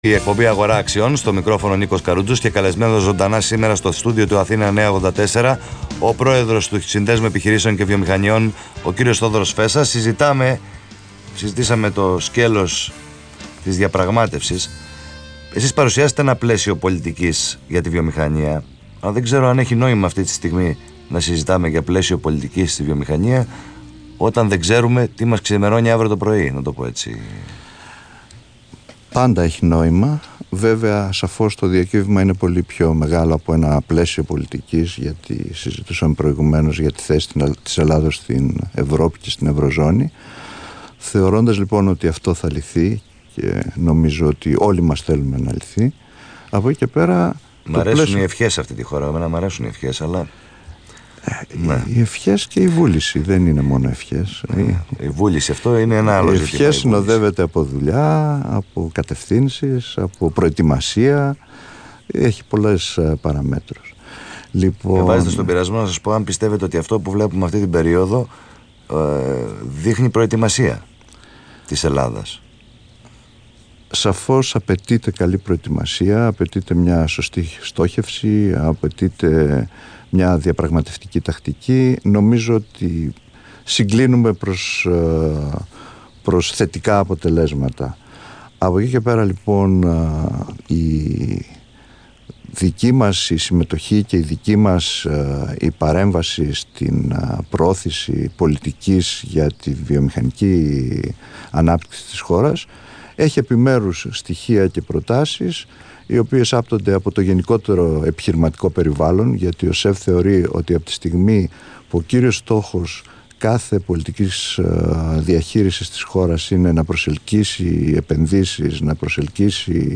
καλεσμένος στον Αθήνα 9.84